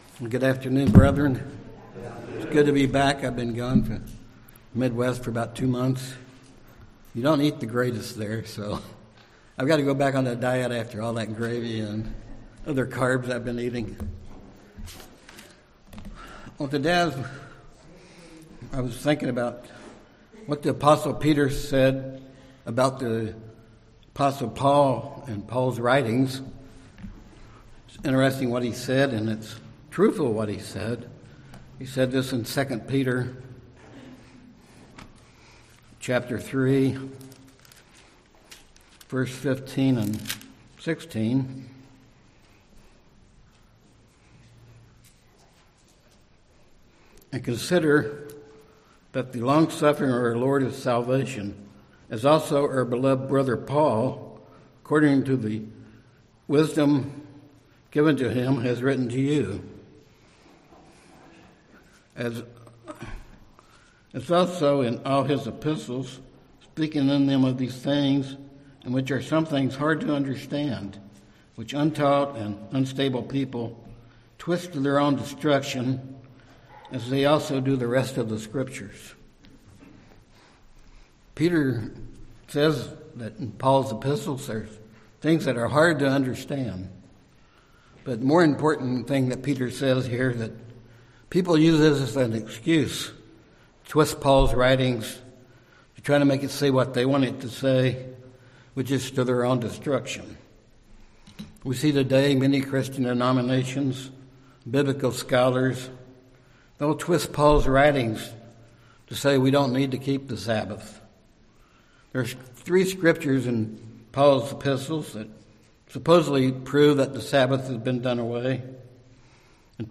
Listen this sermon to learn why the "days" referred to in Romans 14 are not referring to God's Sabbath or annual Holy Days, and why Romans 14:14 does not mean it's alright to eat pork and other unclean foods.
Given in San Jose, CA